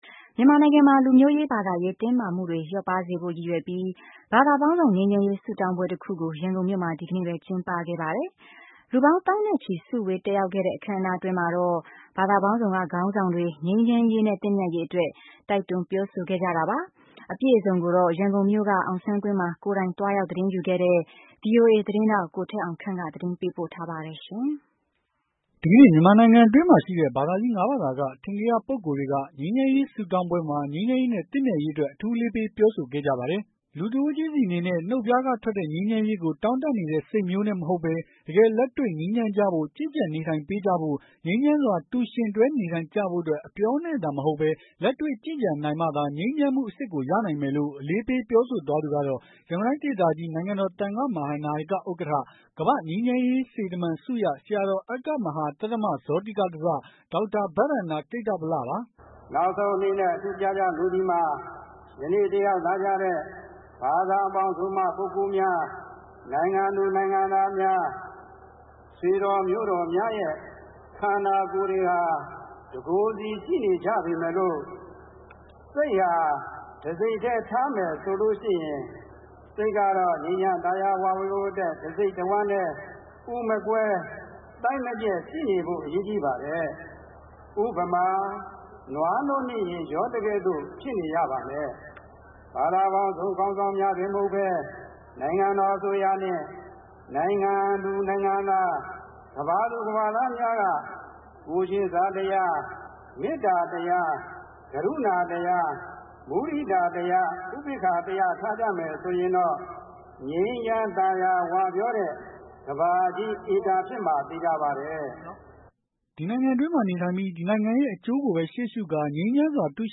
ဘာသာပေါင်းစုံ ငြိမ်းချမ်းရေးဆုတောင်းပွဲ ရန်ကုန်မှာကျင်းပ
ဒီကနေ့ မြန်မာနိုင်ငံတွင်းမှာ ရှိတဲ့ ဘာသာကြီး ၅ဘာသာ ကအထင်ကရ ပုဂ္ဂိုလ်တွေက ငြိမ်းချမ်းရေးဆုတောင်းပွဲမှာ ငြိမ်းချမ်းရေးနဲ့ သင့်မြတ်ရေး အတွက် အထူးအလေးပေးပြောဆိုခဲ့ကြပါတယ်။